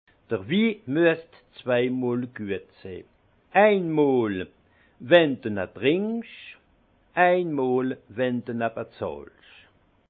Haut Rhin
Ville Prononciation 68
Munster